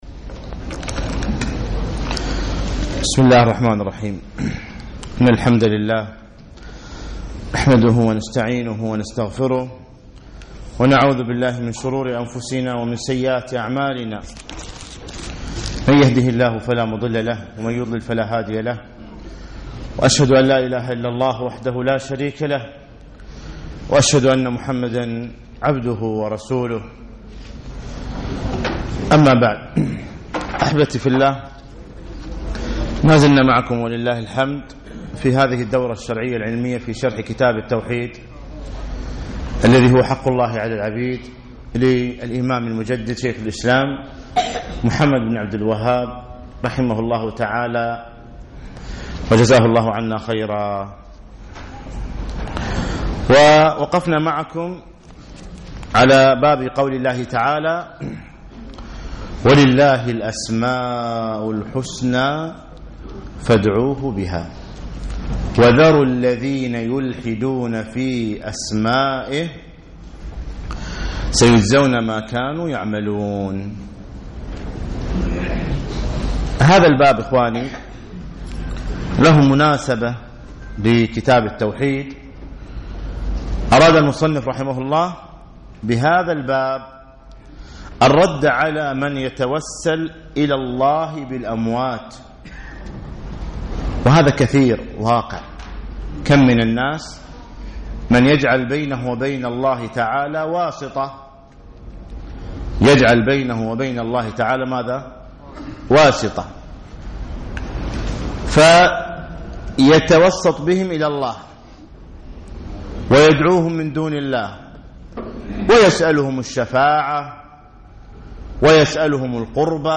يوم الأربعاء 2 9 2015 بعد صلاة المغرب بمسجد عطارد بن حاجب
الدرس التاسع والعشرون